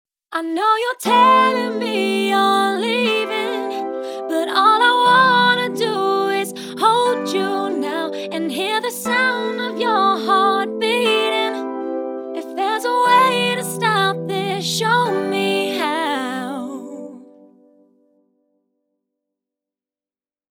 mautopitch-slow-tuning.mp3